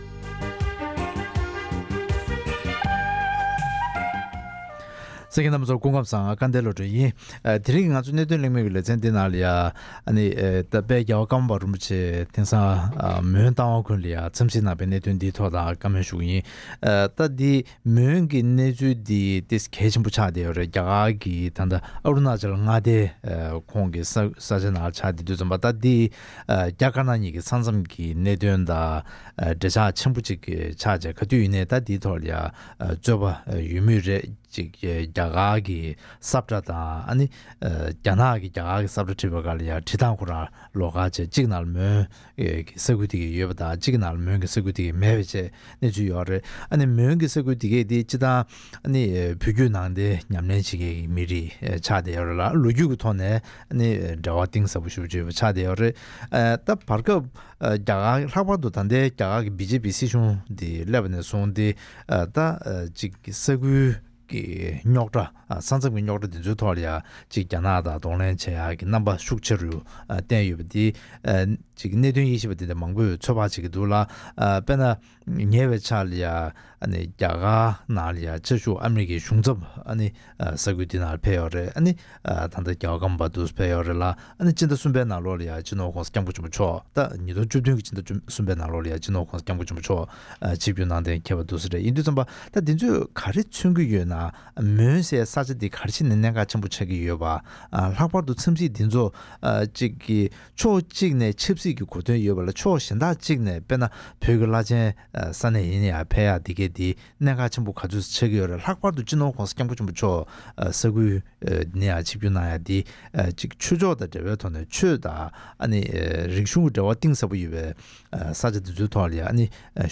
ཀརྨ་པ་མཆོག་མོན་རྟ་དབང་ཁུལ་དུ་འཚམས་གཟིགས་གནང་བའི་གནད་དོན་ཐད་གླེང་མོལ།